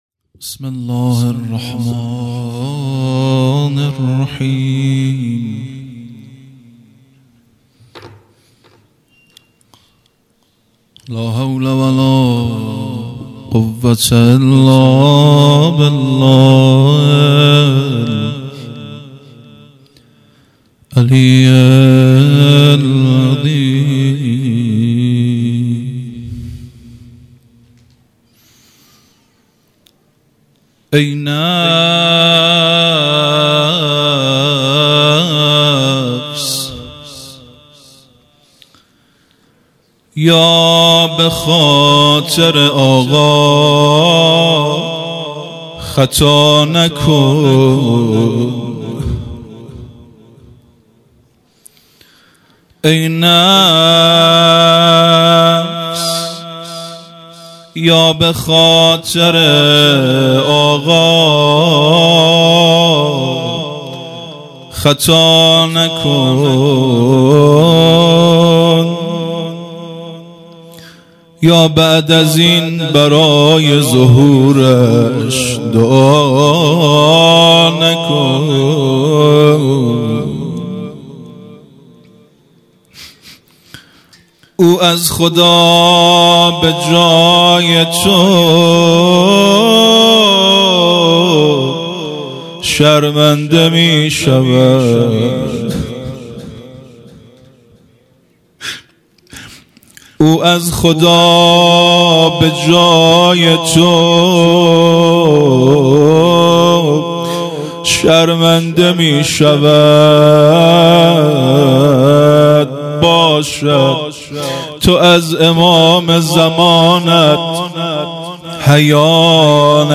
هیئت مکتب الزهرا(س)دارالعباده یزد - روضه | ای نفس بیا بخاطر اقا مداح